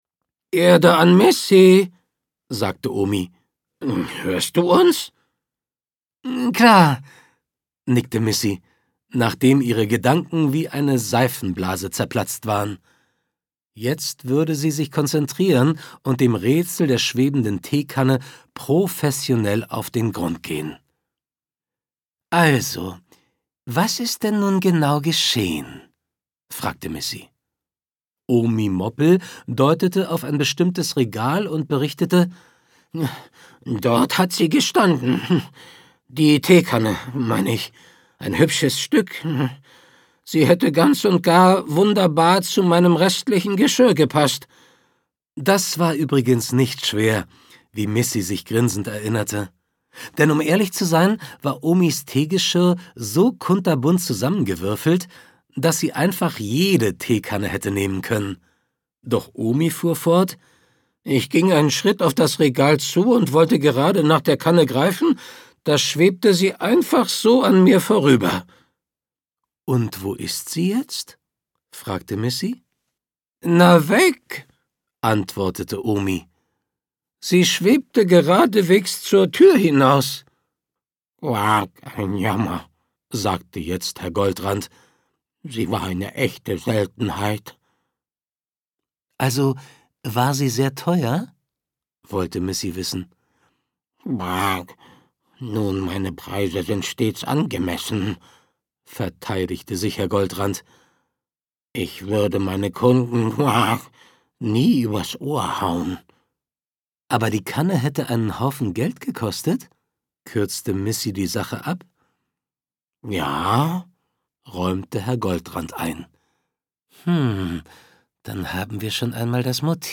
Schlagworte Detektivgeschichte • Detektiv Katze • Für kleine Krimifans • Hörbuch für Kinder • Hörbuchkrimi • Hörspaß • Kinderhörbuch • Kinderkrimi • Krimi für Kinder • lustiges Hörbuch • Missi Moppel • spannende Abenteuer • Tiergeschichte • zum Miträtseln